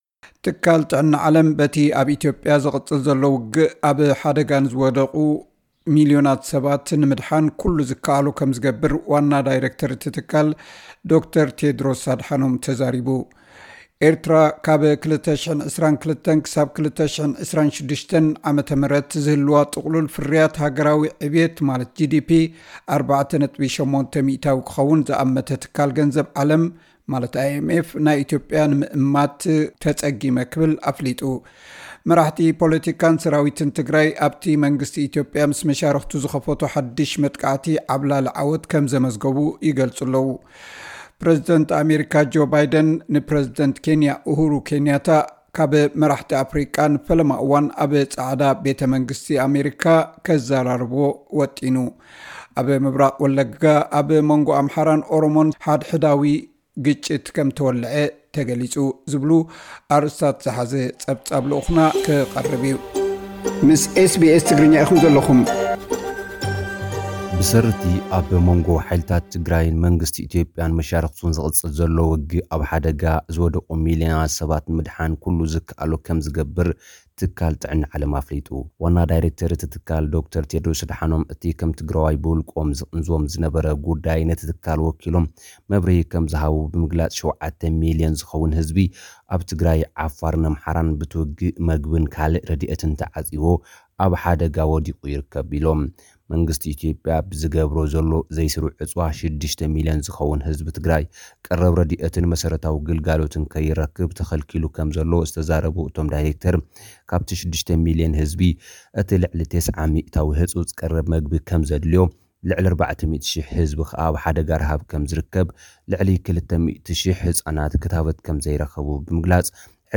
ዝብሉ ኣርእስታት ዝሓዘ ጸብጻብ ልኡኽና ክቐርብ እዩ።